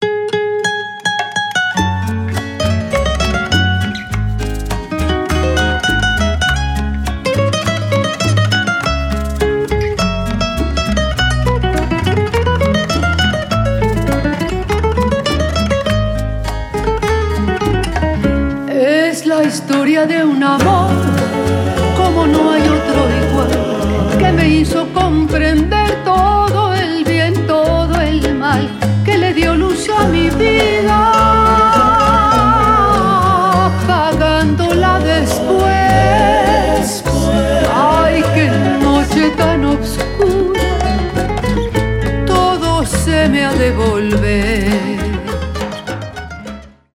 романтические , поп